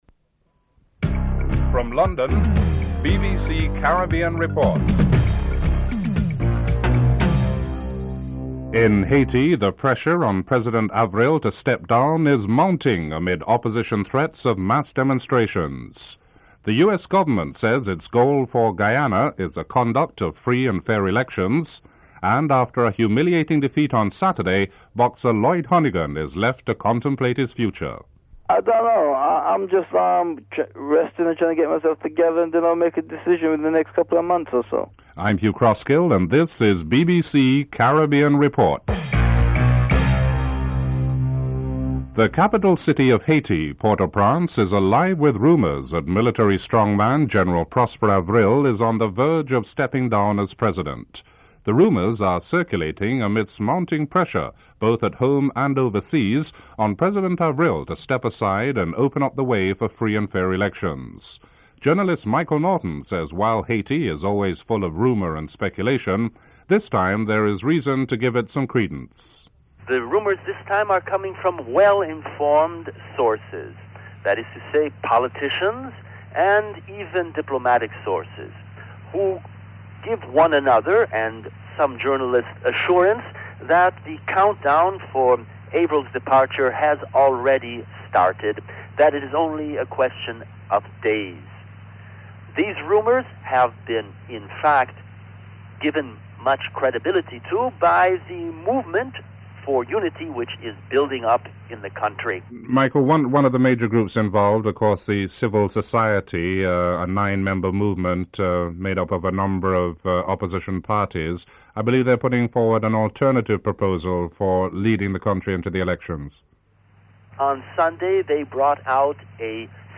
Includes a musical interlude at the beginning of the report.